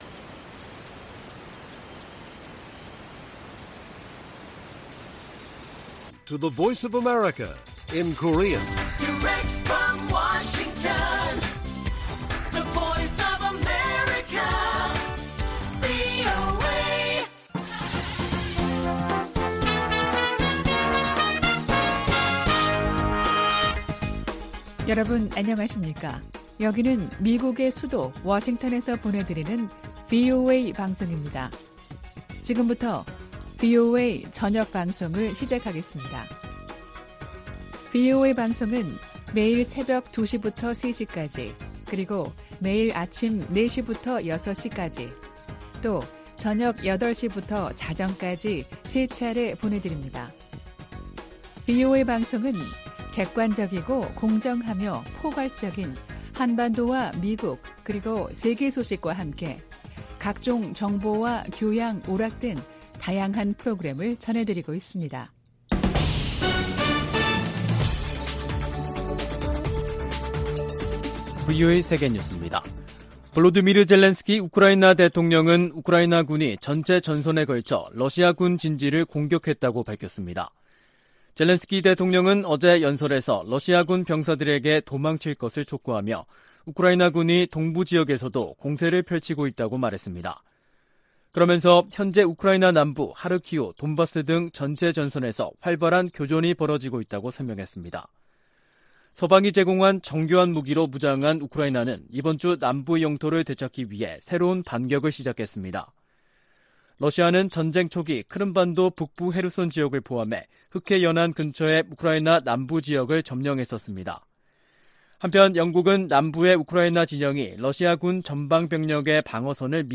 VOA 한국어 간판 뉴스 프로그램 '뉴스 투데이', 2022년 8월 31일 1부 방송입니다. 북한이 7차 핵실험을 할 경우 미국은 한국 등 역내 동맹국의 안보를 위해 추가 행동에 나설 것이라고 백악관이 시사했습니다. 북한이 핵무기 능력 고도화에 따라 생화학무기와 사이버 역량을 공격적으로 활용할 가능성이 커졌다는 분석이 나왔습니다. 북한이 인도의 민간단체에 식량 지원을 요청한 사실은 식량 사정의 심각성을 반영하는 것이라는 분석이 나오고 있습니다.